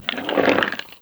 MONSTER_Slime_02_mono.wav